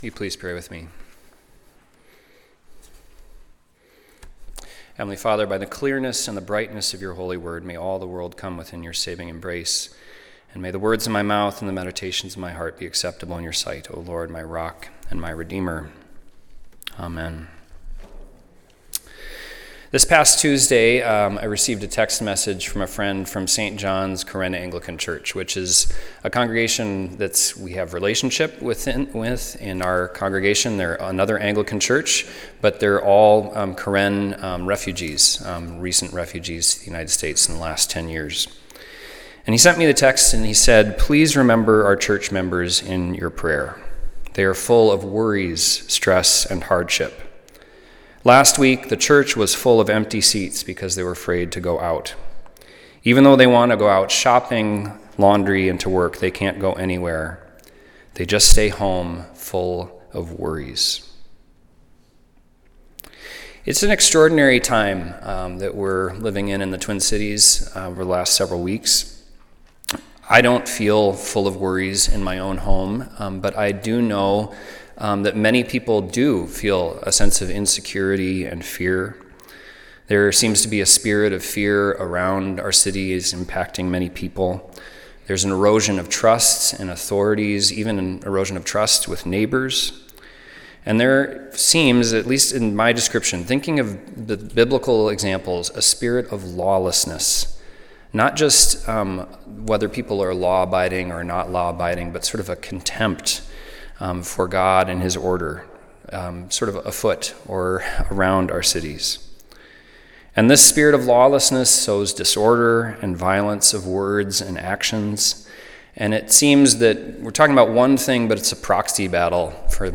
Sunday Worship–January 18, 2026 - Church of the Redeemer